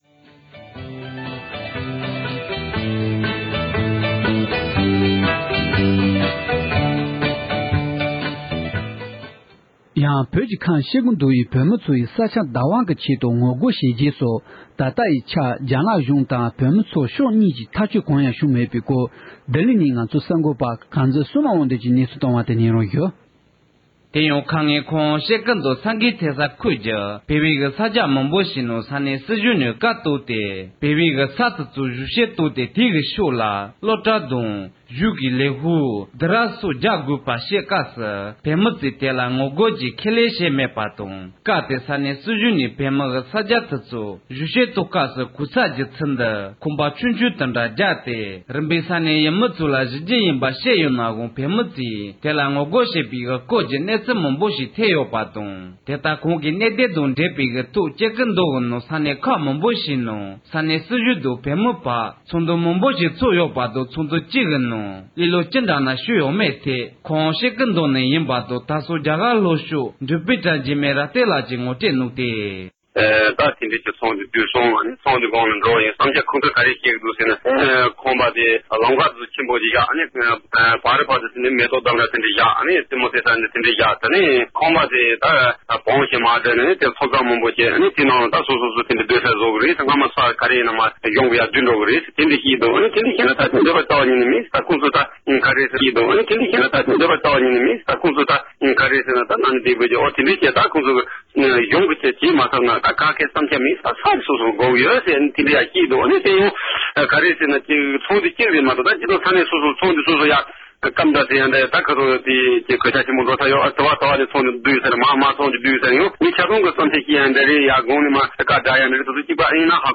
སྒྲ་ལྡན་གསར་འགྱུར།
ཕ་ཡུལ་སྐྱེ་རྒུ་མདོ་ནས་ཡིན་པའི་བོད་མི་ཞིག་གིས།